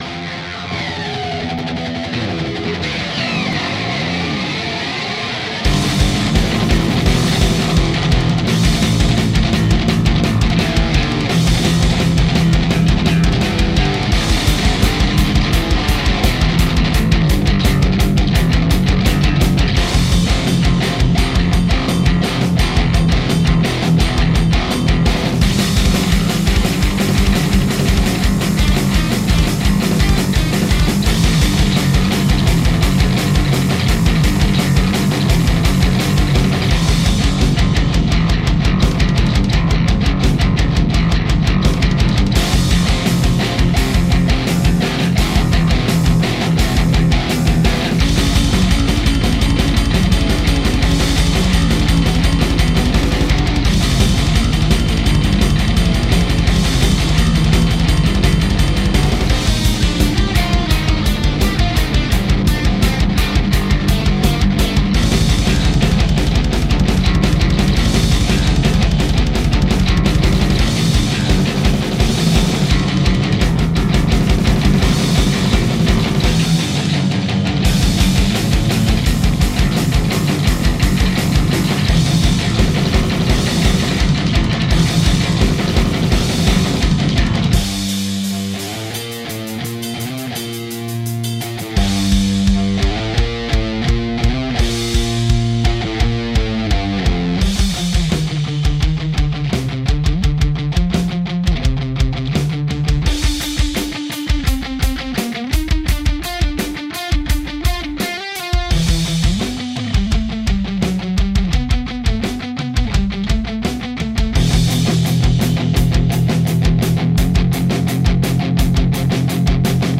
Music / Rock
guitar